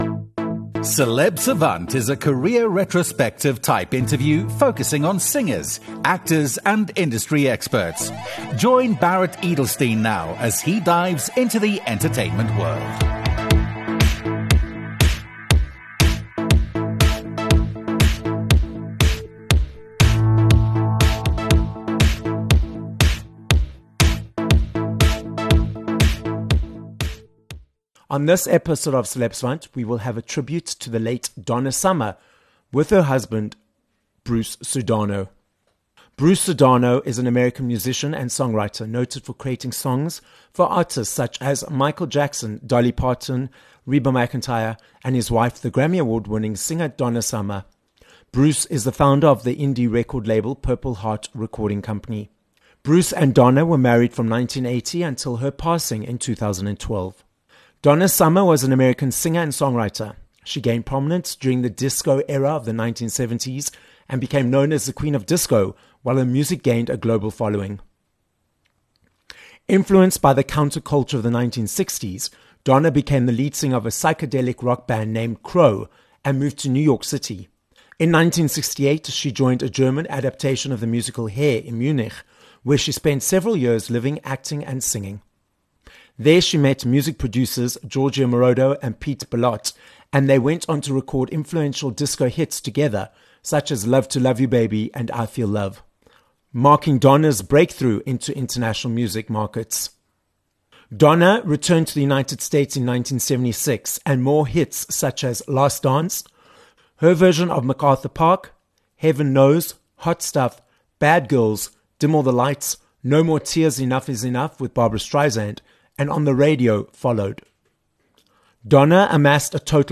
Celebrating the 250th episode of Celeb Savant, we will be paying tribute to the late American singer and songwriter Donna Summer, as we’re joined by her husband - singer, songwriter and musician, Bruce Sudano. With over 150 million records sold, 5 Grammys and 6 American Music Awards, 32 top Billboard chart hits, and a career that spanned over 4 decades, we hear from Bruce about Donna's life, success, creativity, art and music… that has inspired millions of people around the world.